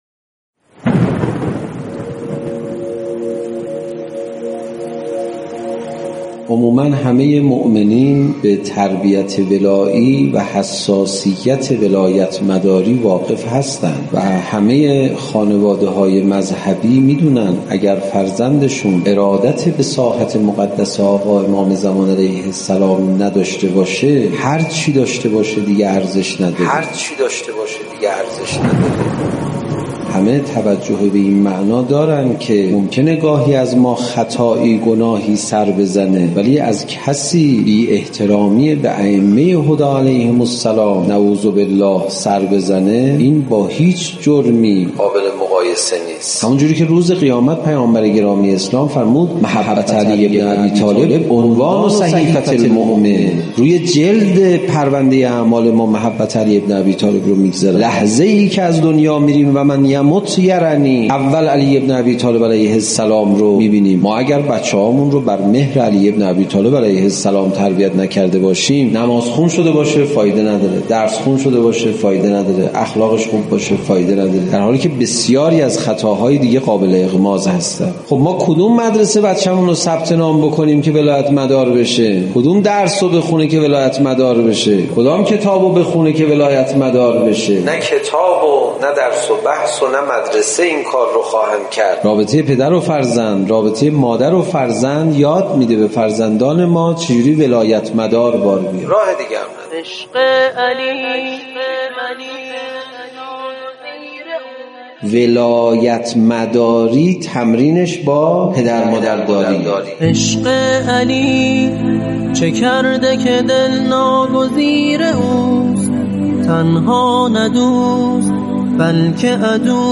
فرازی از سخنرانی استاد پناهیان